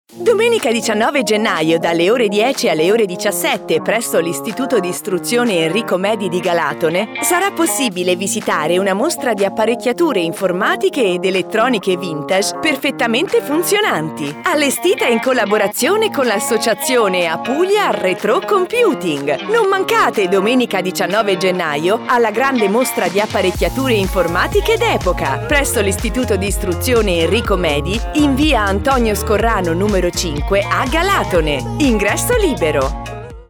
(Spot radiofonico Mostra di retrocomputing a Galatone)
spot_mostra_retrocomputing.mp3